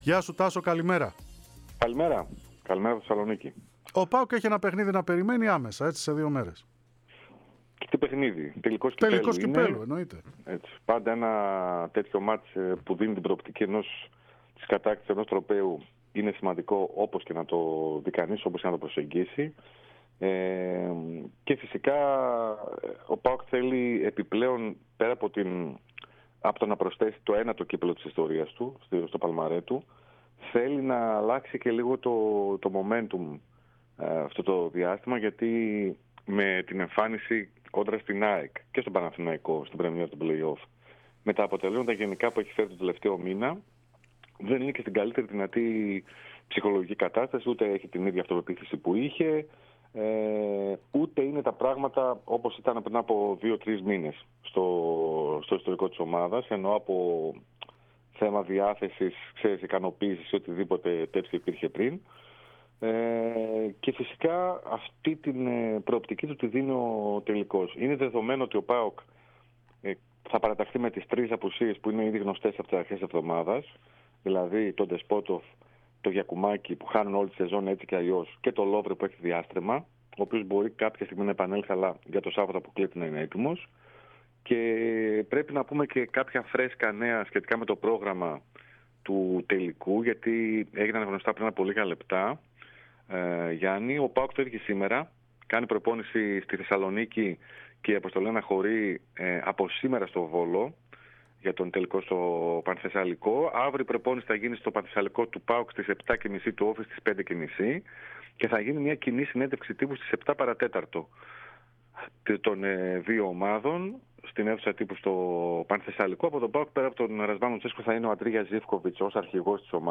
Το ρεπορτάζ